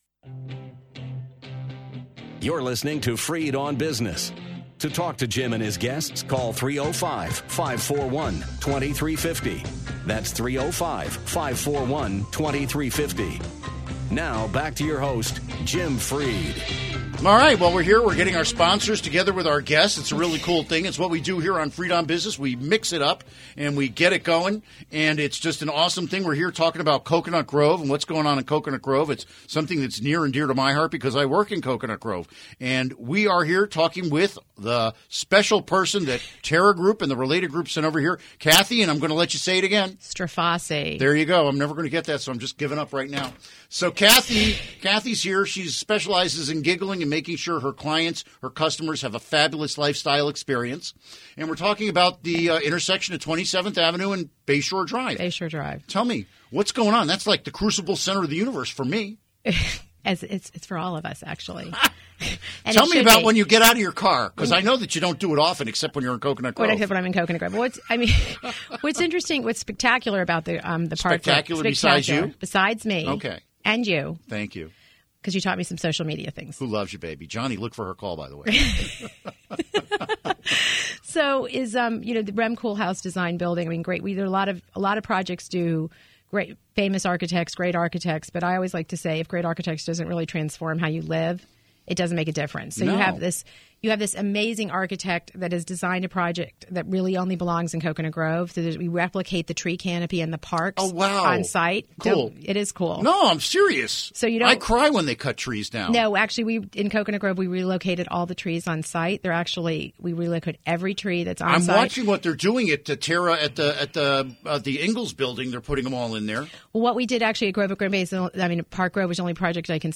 We discuss the evolution of the Coconut Grove real estate market. Interview Segment Episode 366: 04-28-16 Click here to download Part 1 (To download, right-click and select “Save Link As”.)